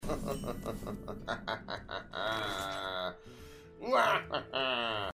Play Muhaha, Download and Share now on SoundBoardGuy!
PLAY muhahahaha
muhaha.mp3